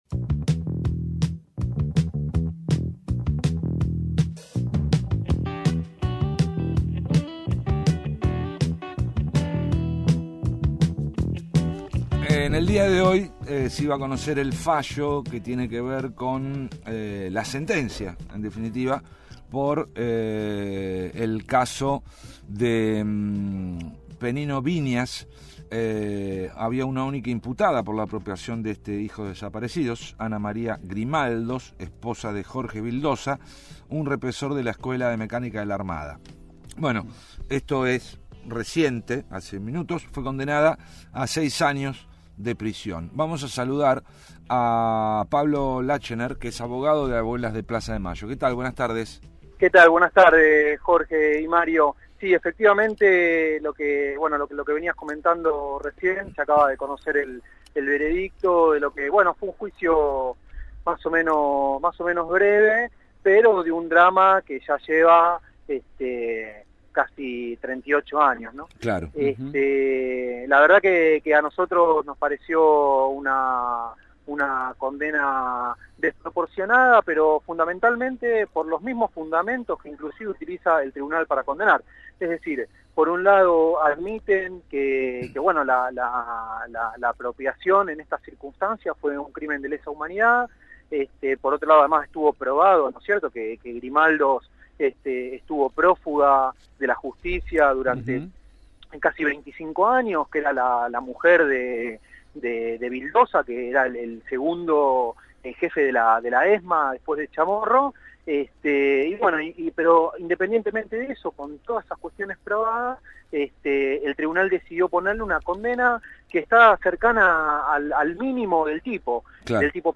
Lunes a viernes de 13 a 16 hs. por FM 107.5